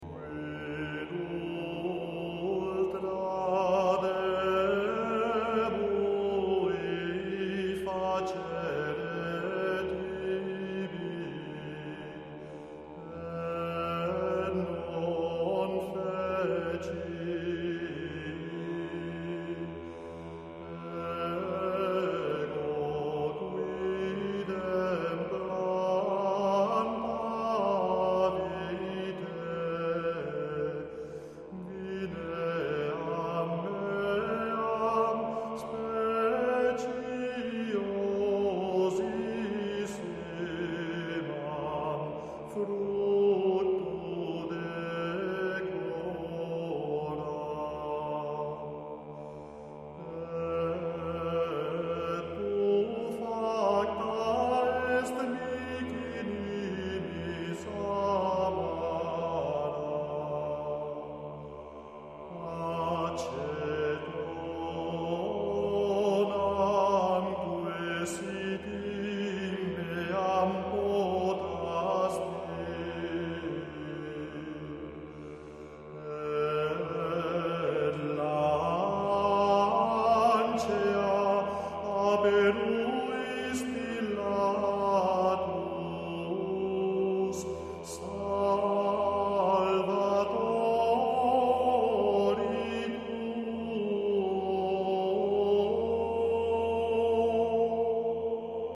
L'esecuzione qui proposta è quella di Marcel Pérès del 1991 sulla scorta di codici del rito romano antico (pubblicati da Max Lütolf, Das Graduale von Santa Cecilia in Trastevere, Köln–Genève: Fondation Martin Bodmer, 1987) tenta di ricostruire la pratica del VII secolo, con i paraphonistes (ovvero le voci gravi di bordone), una tecnica ornamentale che la notazione successiva non trascriveva più, e un uso diffuso di un'intonazione non diatonica (che all'orecchio moderno può apparire 'stonata').
Esecuzione sulla scorta di codici del rito romano antico.
Quia eduxi — Ensemble Organum | dir. Marcel Pérès | Cd Harmonia Mundi, 1992